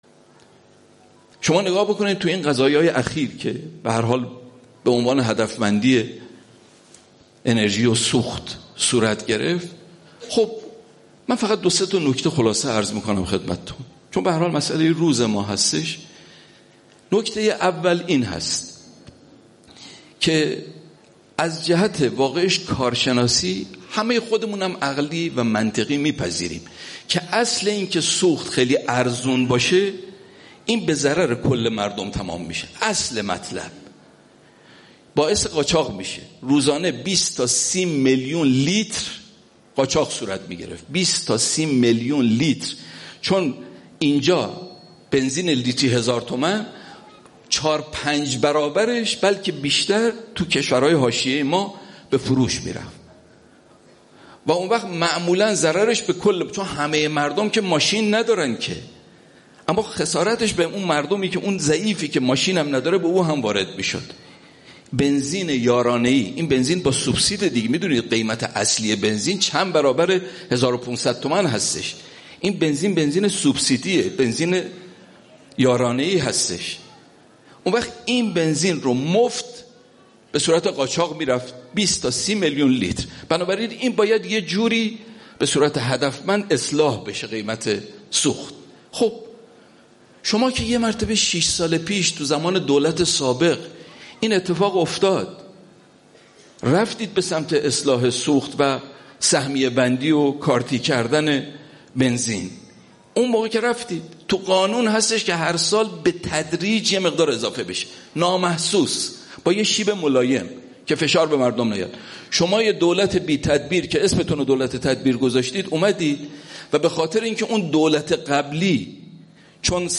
یادواره شهدای شهرک مطهری (چهاردانگه)